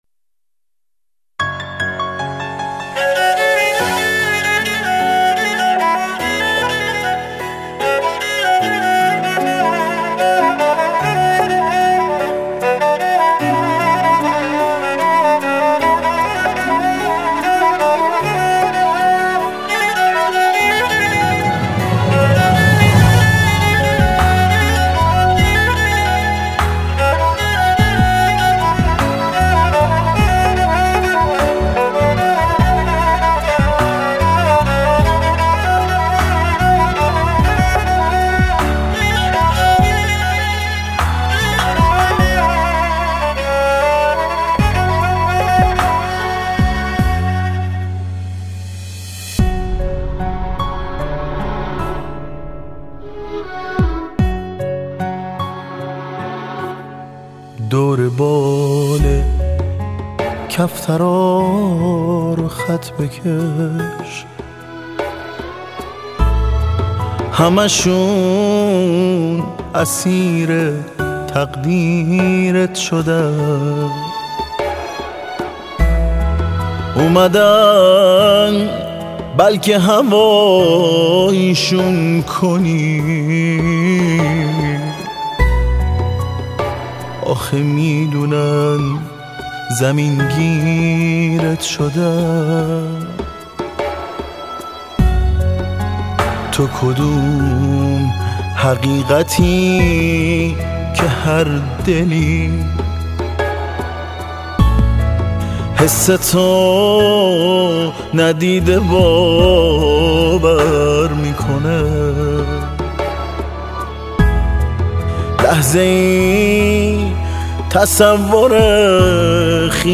1 آخرین مطالب موسیقی موسیقی پاپ